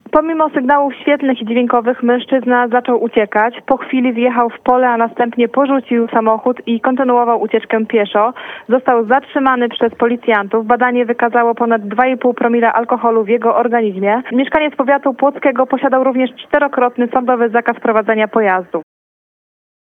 SETKA-POLICJA-10.12.mp3